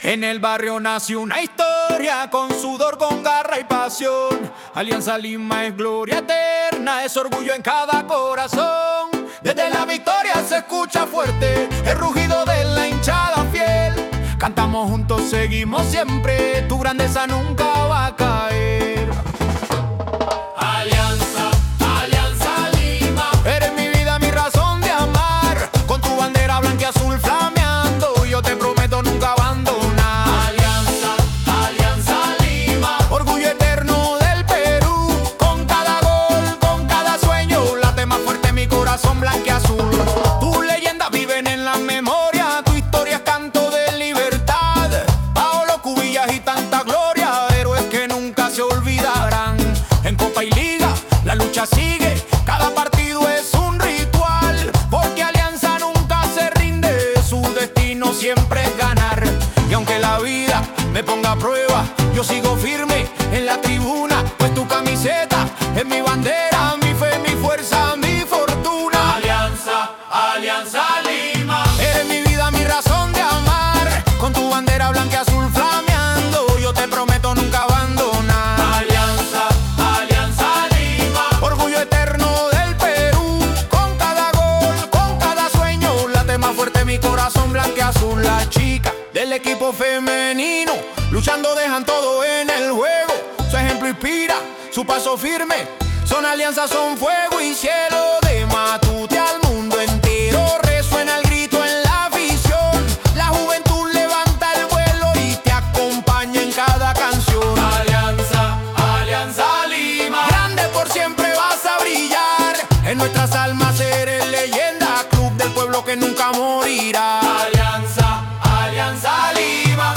Himnos modernos inspirados en la historia, Matute y la pasión eterna del pueblo blanquiazul.
Canción épica dedicada a la hinchada aliancista, a La Victoria